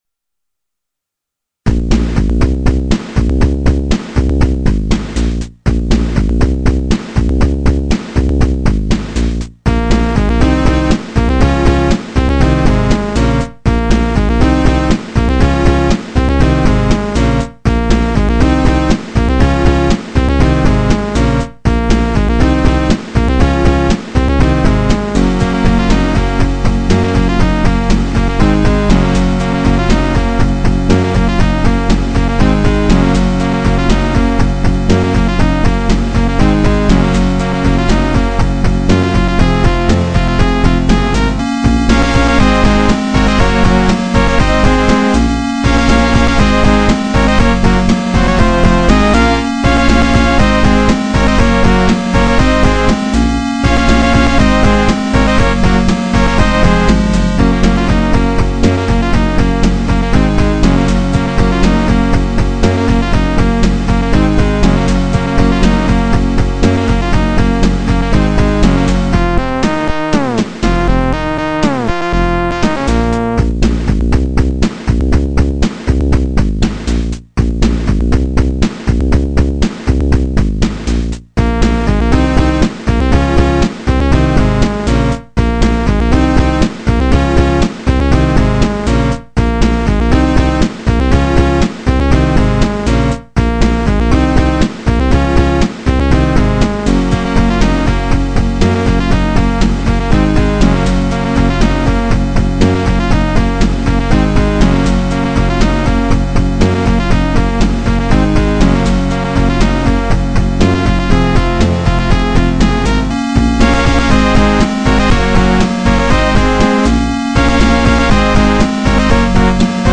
BPM120-120
Audio QualityCut From Video